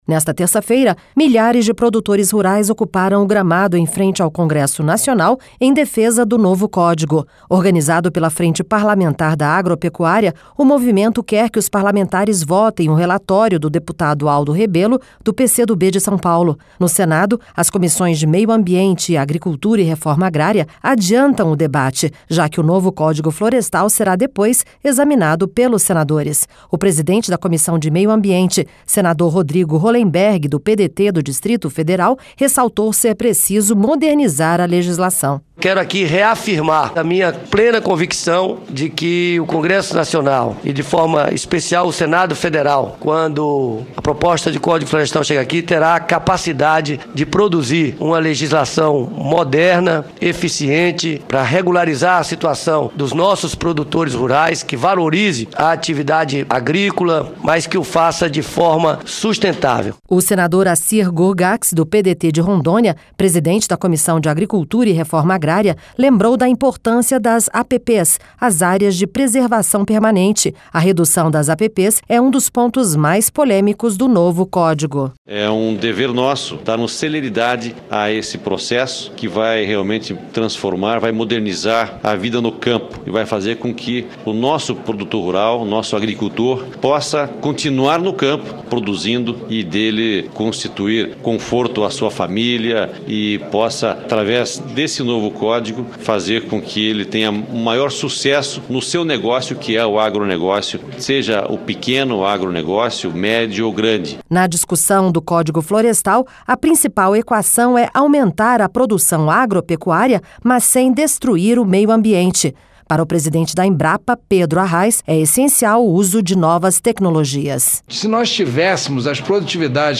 No Senado, as comissões de Meio Ambiente e Agricultura e Reforma Agrária adiantam o debate, já que o novo Código Florestal será depois examinado pelos senadores. O presidente da Comissão de Meio Ambiente, senador Rodrigo Rollemberg, do PDT do Distrito Federal, ressaltou ser preciso modernizar a legislação.